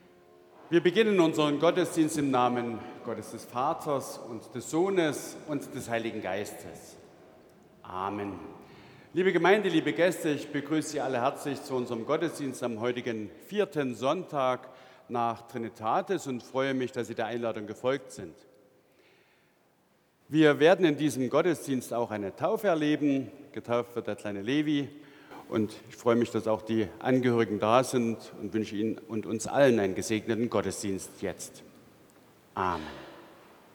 Begrüßung
Audiomitschnitt unseres Gottesdienstes vom 3. Sonntag nach Trinitatis 2025.